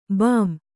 ♪ bām